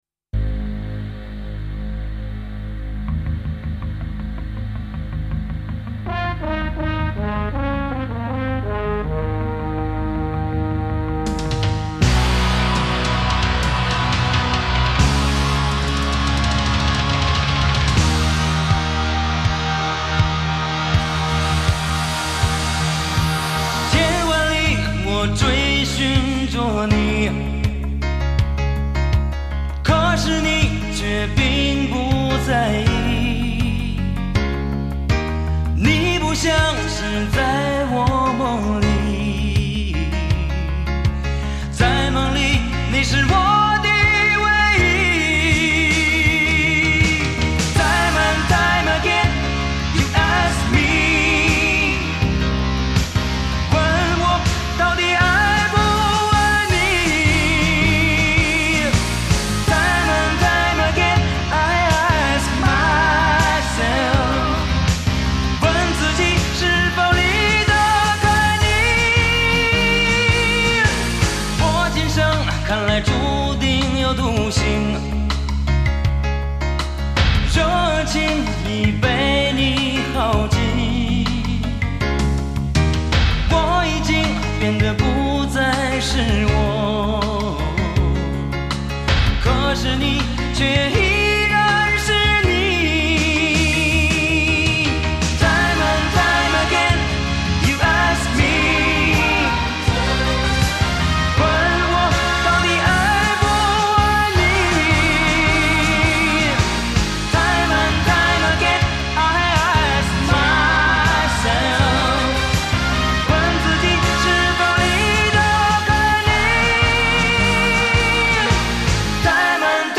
片头曲
主题曲